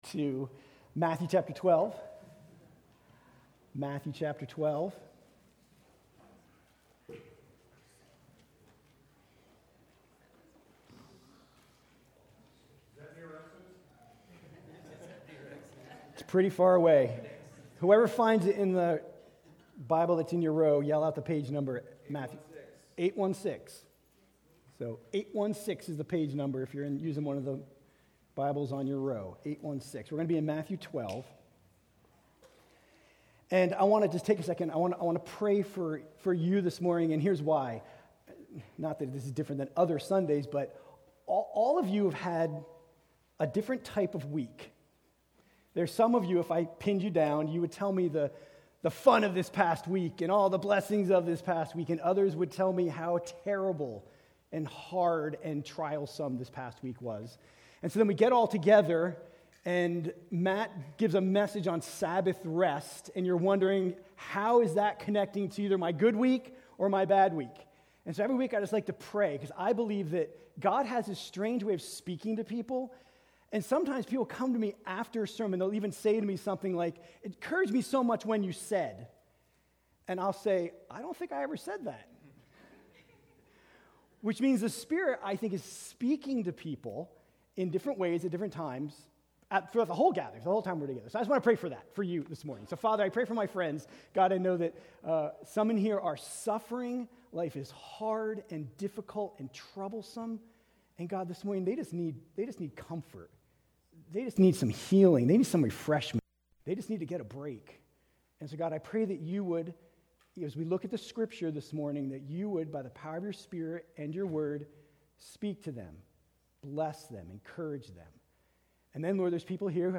Sermons from Christ Church of Mt Airy: Mt Airy, MD